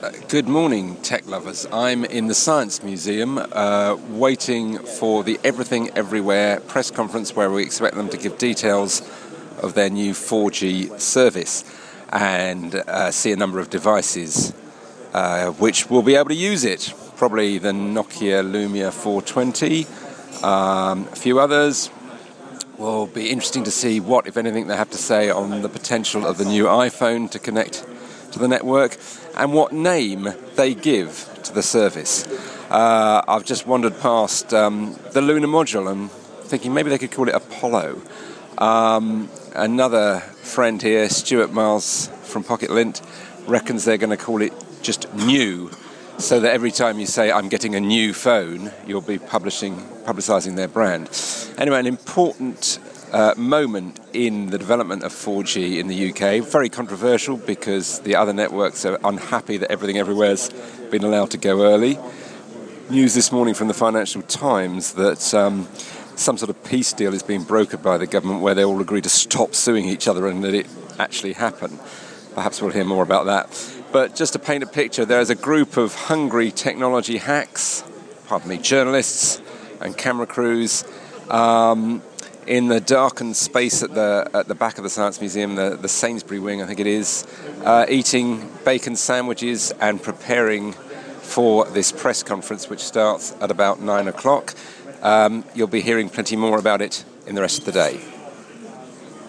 At the Science Museum waiting for Everything Everywhere to unveil its 4G network plans